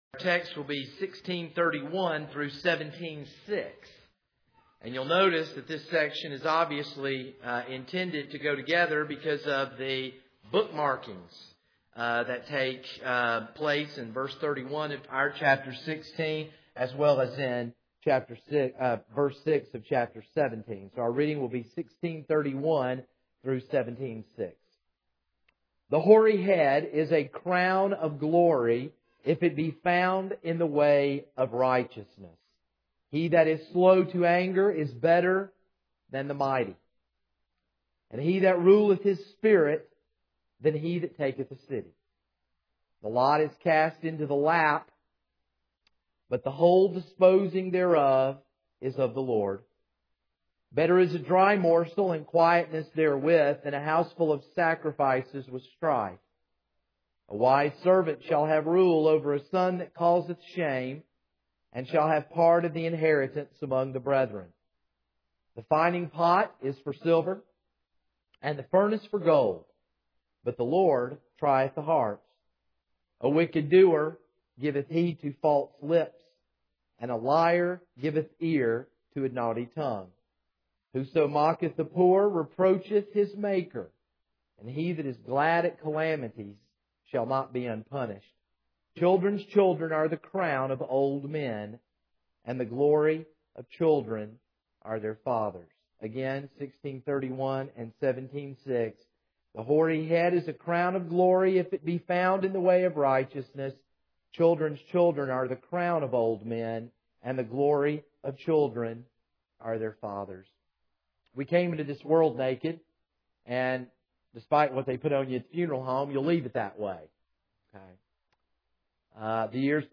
This is a sermon on Proverbs 16:31-17:6.